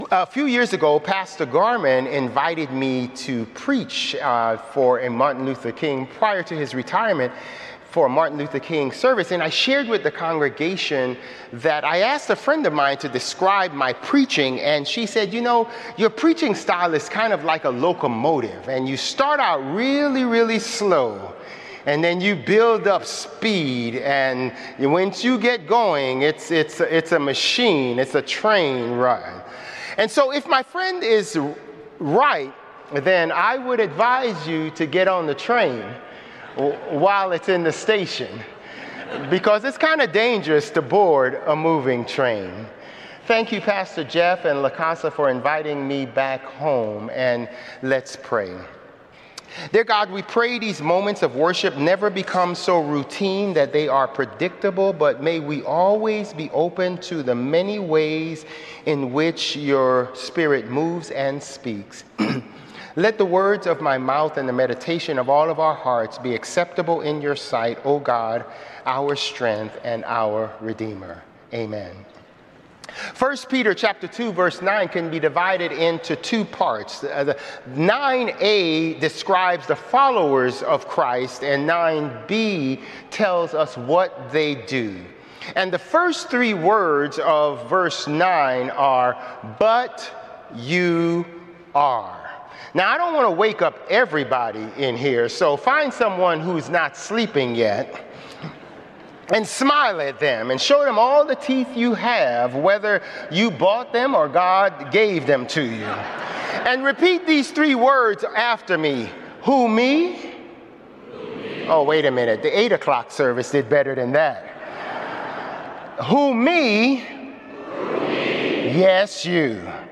Sermons | Bethel Lutheran Church
February 23 Worship